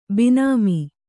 ♪ bināmi